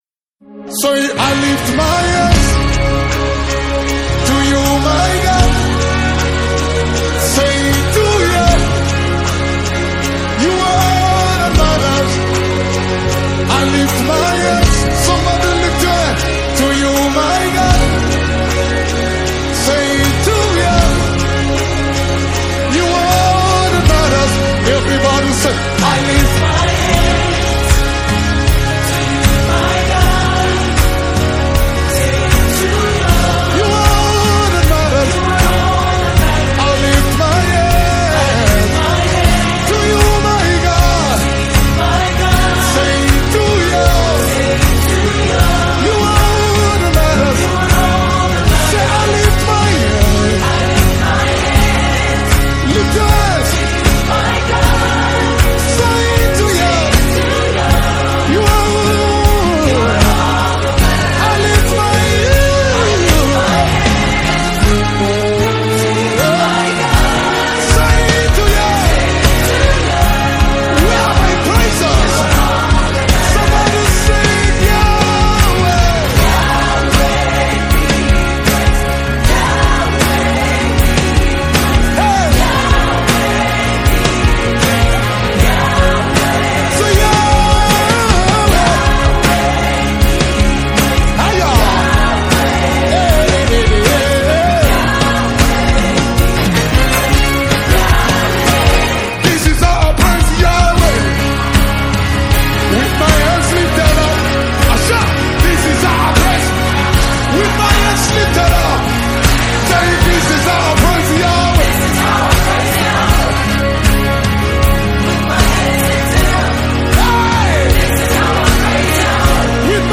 Let Your Sound is a New Song Nigerian Gospel Music Minister